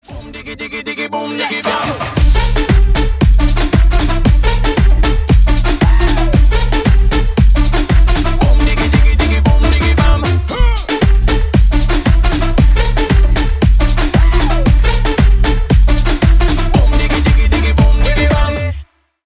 Ремикс # Танцевальные # без слов